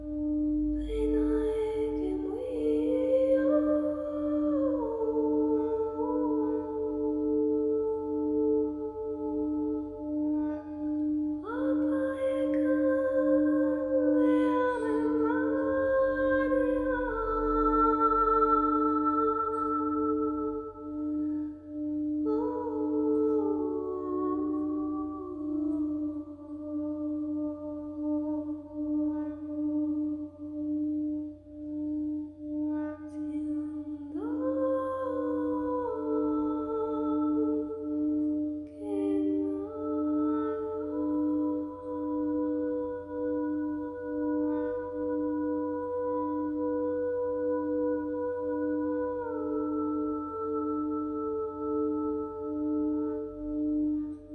Bols Chantants et Voix                    Durée 09:14
Un instant de pure douceur…